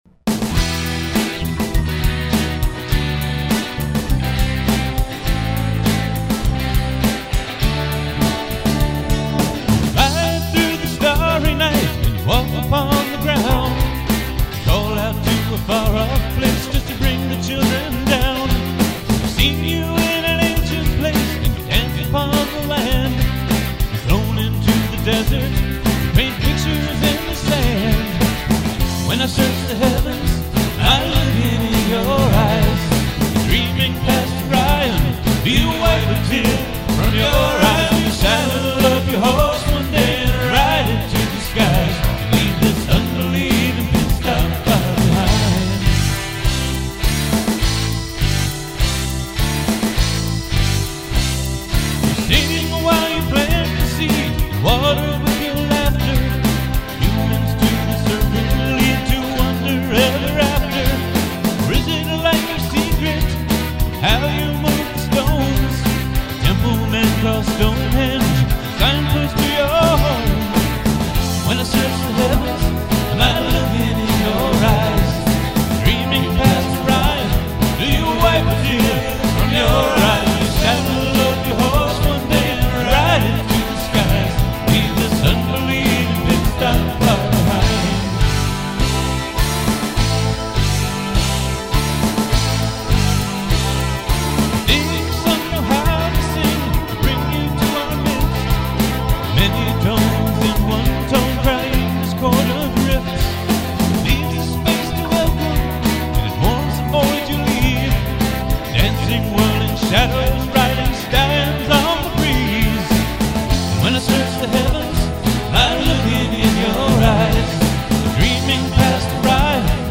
because i was bored i looked through about a million unlabeled cds last night to see if i had a copy of this song. i forget why i was looking for it originally though...... anyway, it was one of the very first things i ever recorded at home. me
i'm sure the mix totally blows, but hey, it was the first effort i ever made and most of it was recorded on an ancient reel to reel recorder that is now long gone.